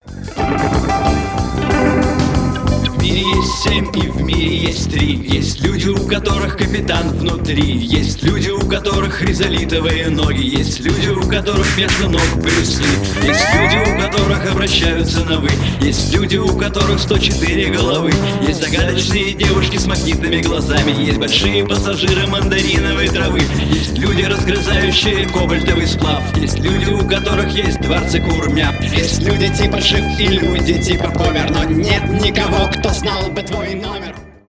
рок
new wave